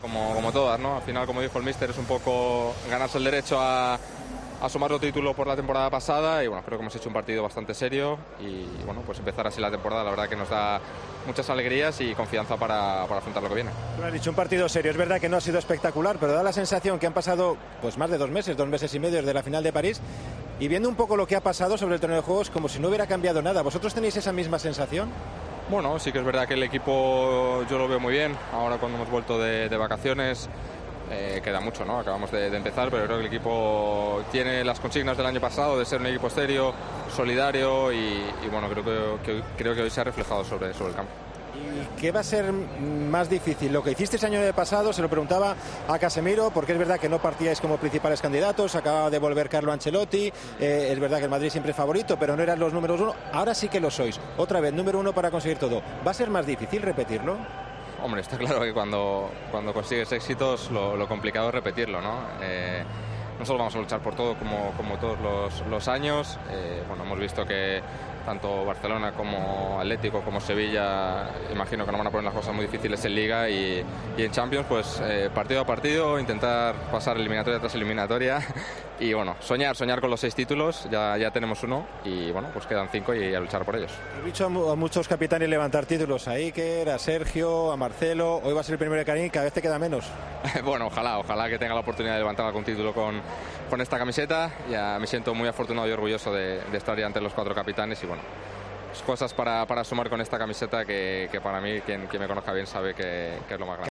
El defensa del Real Madrid habló en Movistar de la victoria ante el Eintracht, que le da los blancos su quinta Supercopa de Europa.